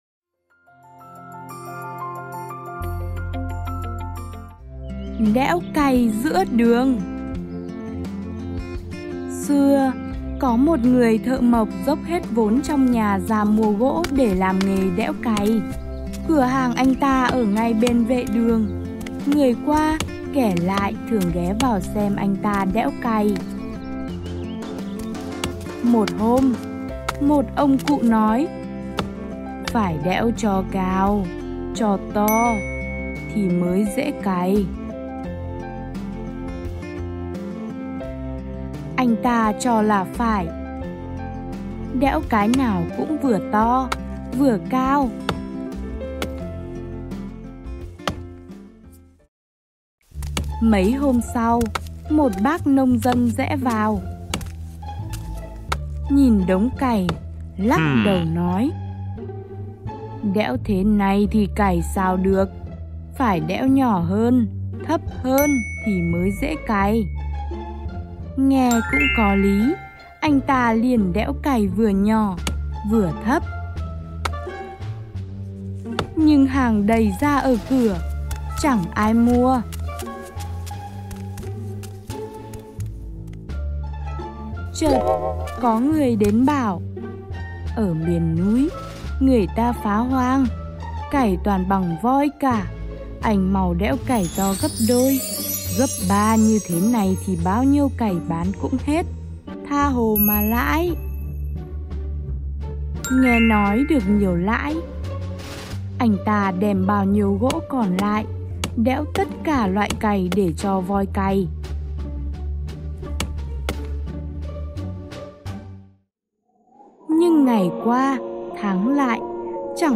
Sách nói | Đẽo cày giữa đường - Ngữ văn 7